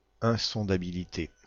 Ääntäminen
Ääntäminen (France): IPA: /ɛ̃.sɔ̃.da.bi.li.te/ Haettu sana löytyi näillä lähdekielillä: ranska Käännös Substantiivit 1. unfathomability Määritelmät Substantiivit Nature insondable .